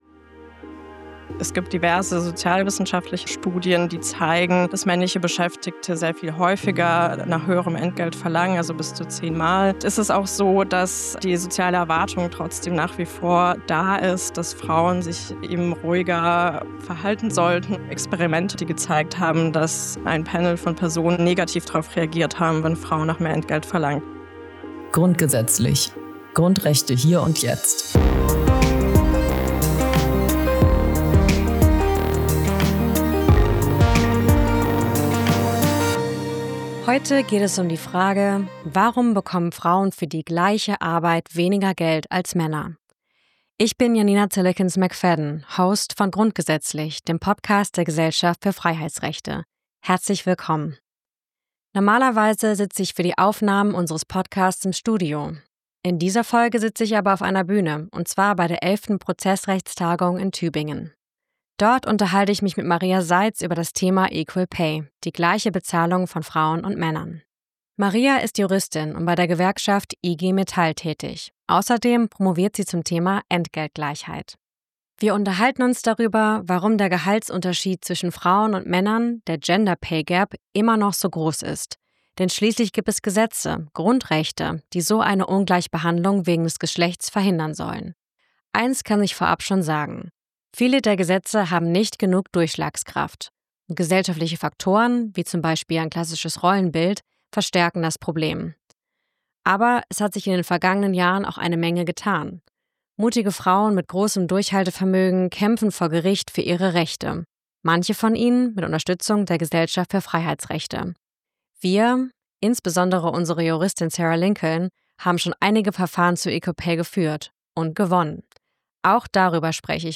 Grundgesetzlich Live Podcast auf der 11. Prozessrechtstagung in Tübingen